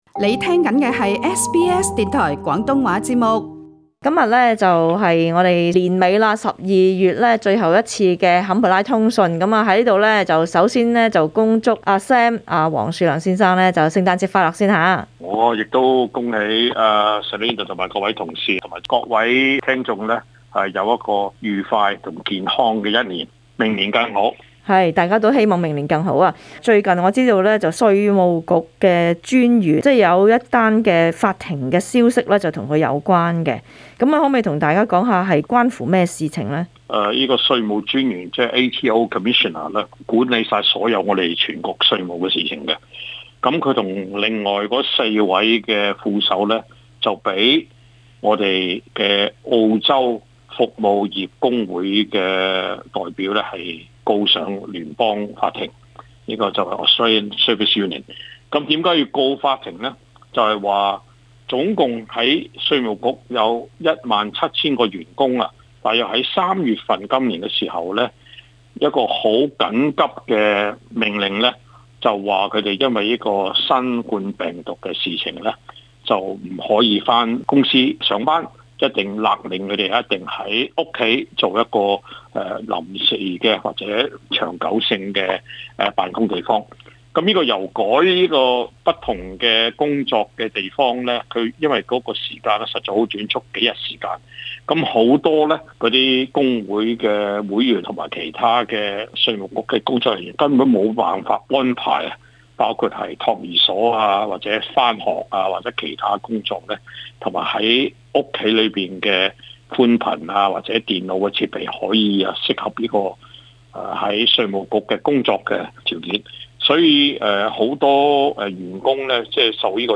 【坎培拉通訊】